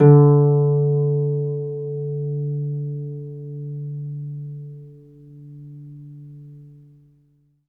HARP EN3 SUS.wav